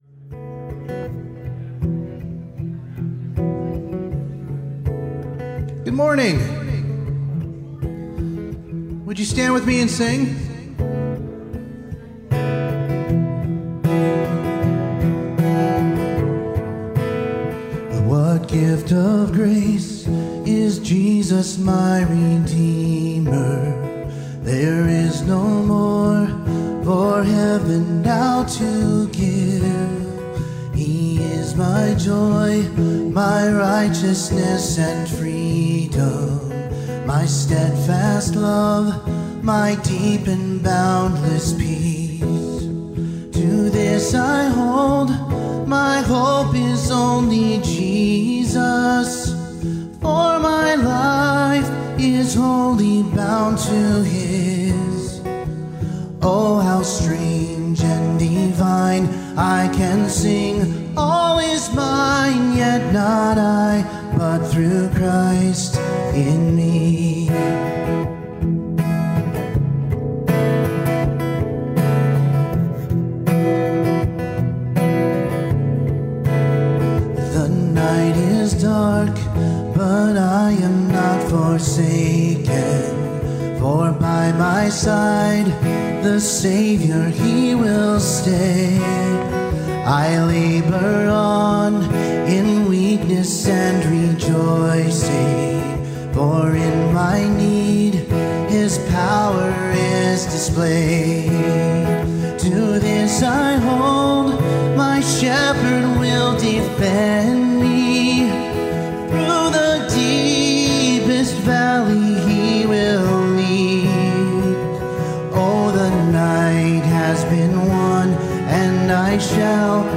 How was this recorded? Ephesians 6:14-18 Service Type: Sunday Morning Youversion Event THE ARMOR OF GOD